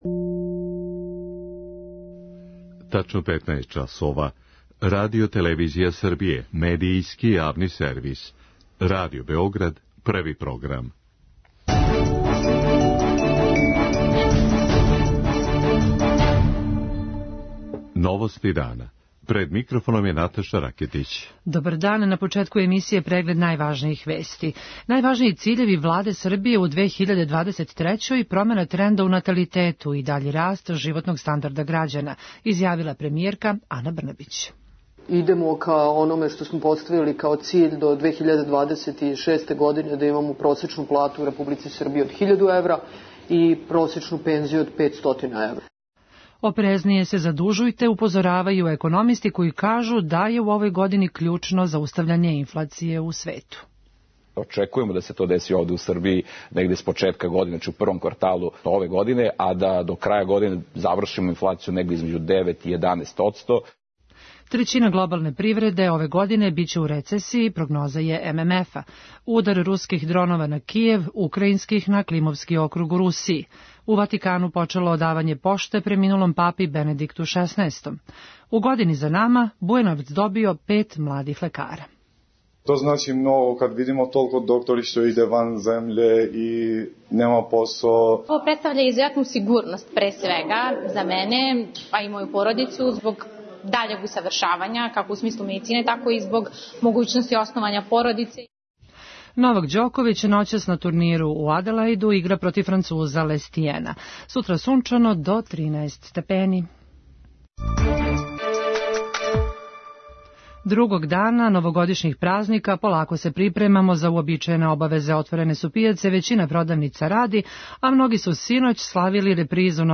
Очекује у овој години даљу нестабилност на Космету. преузми : 6.03 MB Новости дана Autor: Радио Београд 1 “Новости дана”, централна информативна емисија Првог програма Радио Београда емитује се од јесени 1958. године.